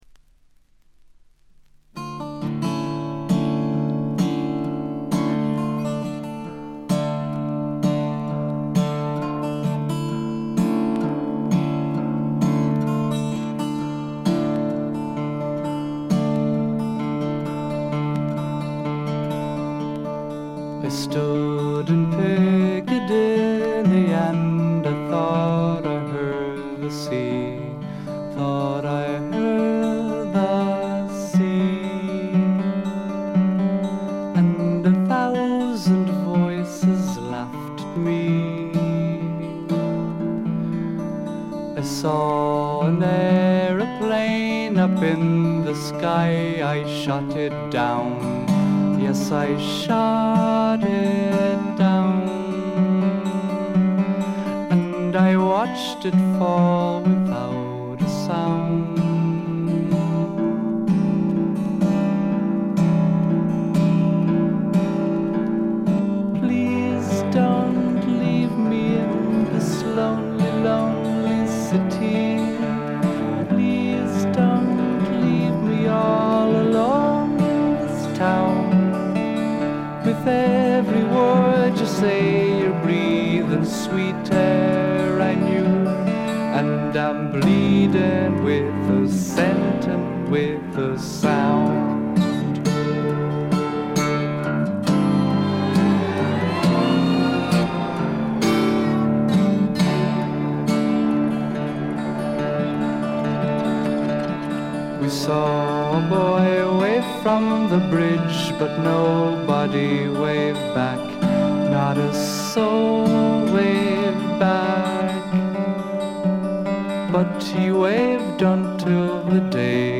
基調は霧の英国フォークでありながらも、幻想的で、ドリーミーで、浮遊感たっぷりで、アシッドな香りも・・・。
試聴曲は現品からの取り込み音源です。